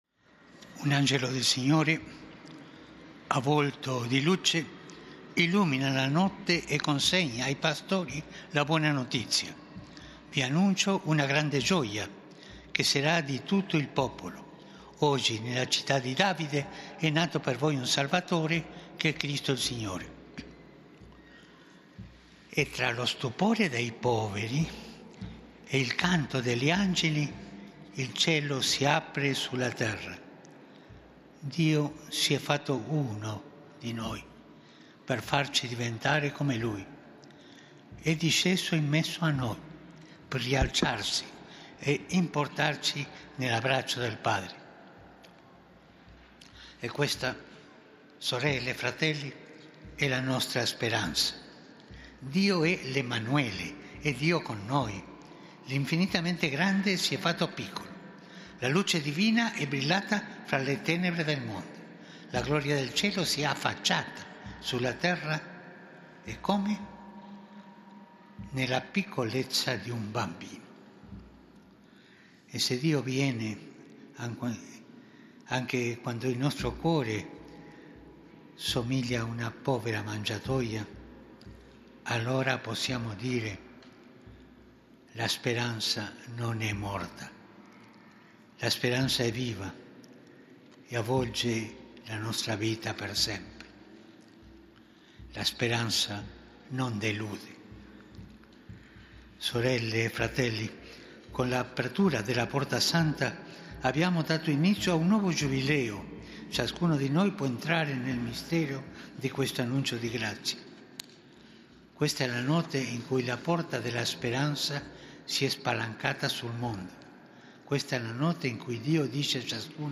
FROM THE CHAPEL OF CASA SANTA MARTA, RECITATION OF THE ANGELUS PRAYER LED BY POPE FRANCIS